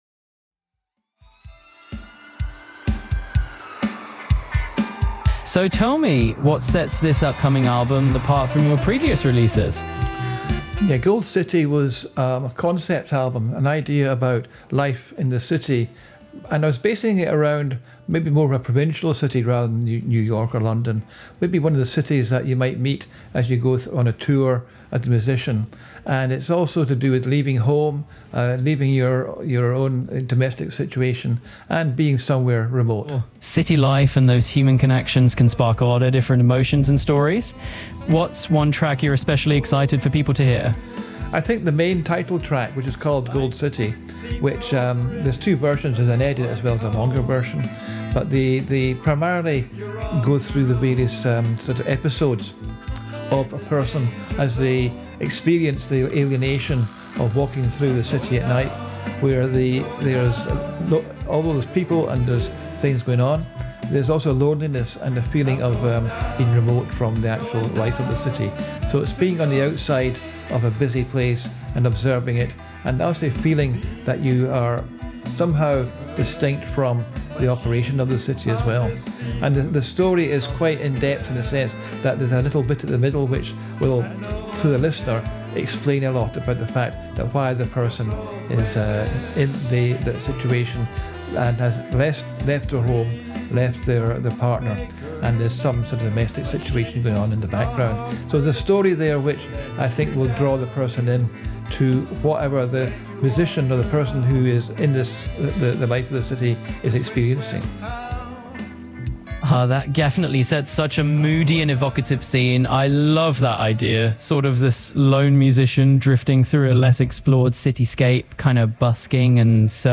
Podcast Interview